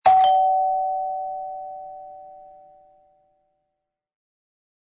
• God lyd
Mekanisk ringeklokke med lekkert design.
Honeywell D107 – Facet / Mekanisk dørklokke (kablet)